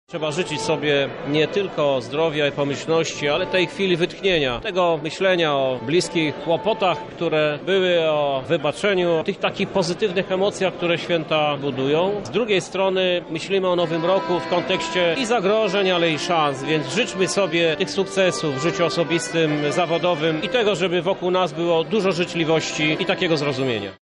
– mówi Krzysztof Żuk, prezydent Lublina.